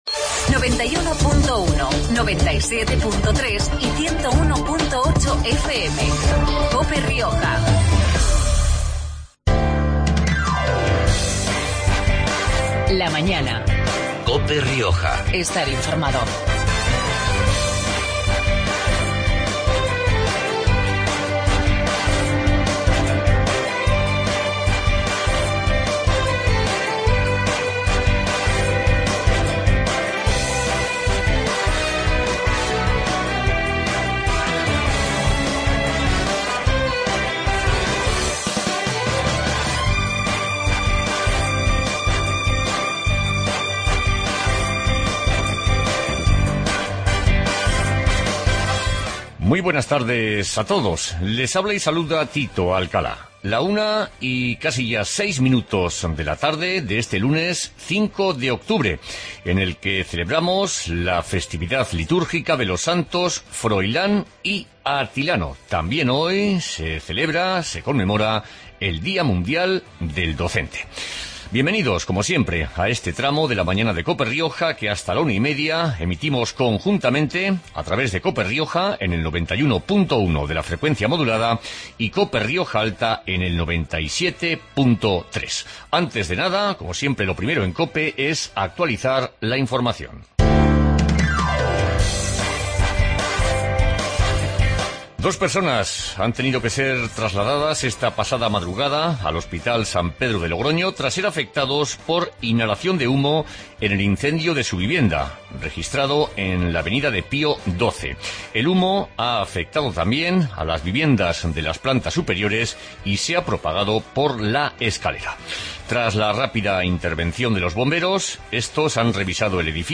Magazine de actualidad riojana